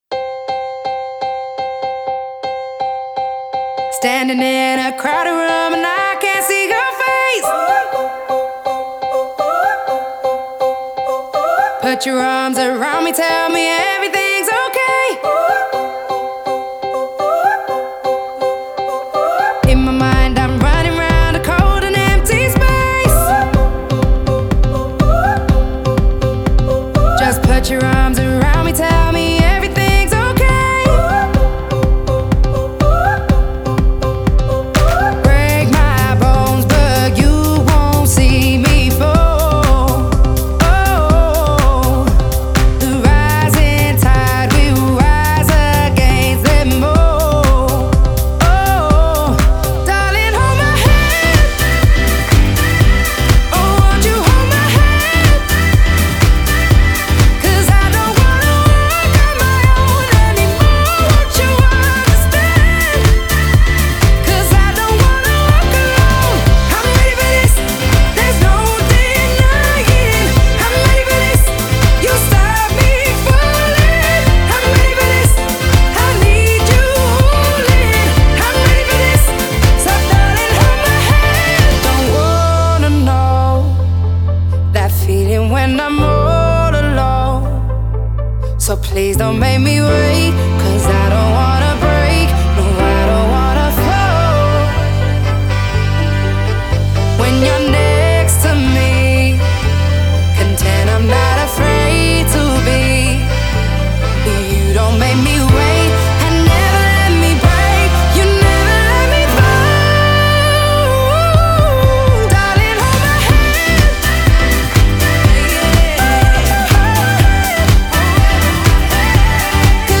BPM62-123
Audio QualityMusic Cut